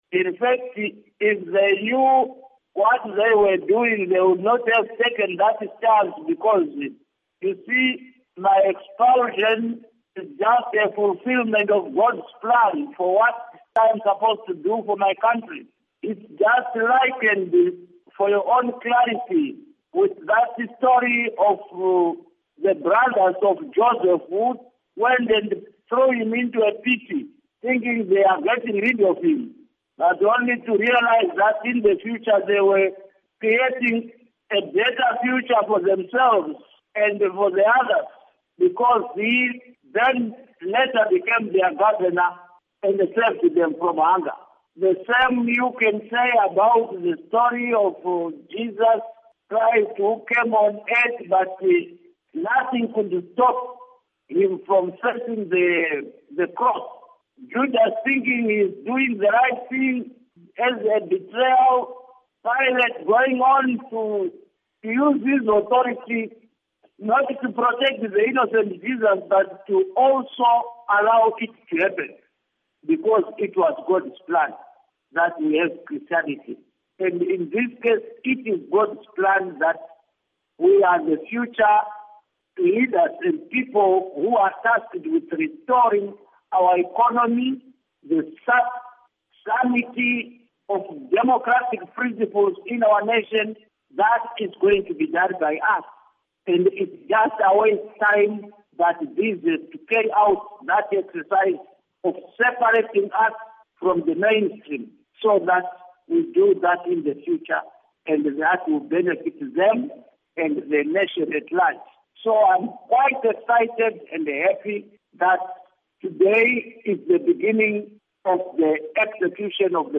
Interview With Kudakwashe Bhasikiti on Zanu PF Expulsion